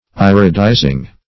Search Result for " iridizing" : The Collaborative International Dictionary of English v.0.48: Iridize \Ir"i*dize\, v. t. [imp.